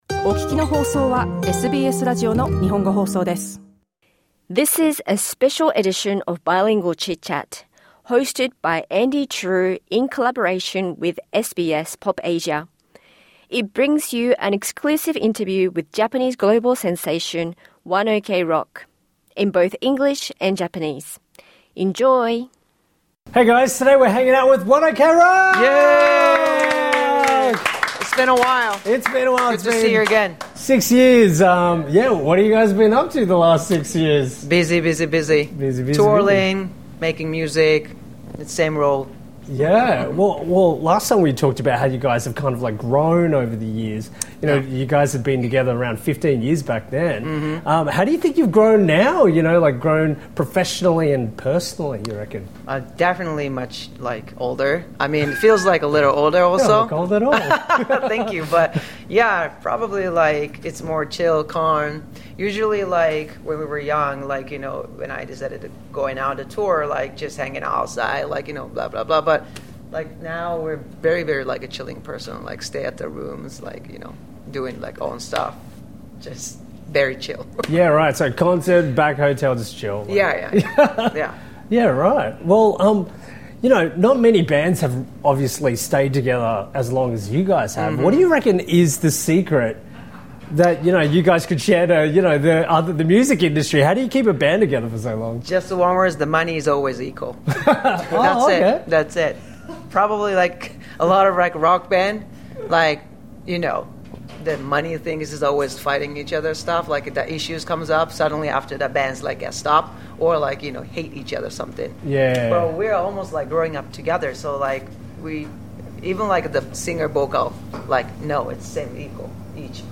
SBS PopAsia spoke with ONE OK ROCK during their visit to Australia. In this special edition of Bilingual Chitchat, we bring you a near-uncut interview featuring a mix of Japanese and English!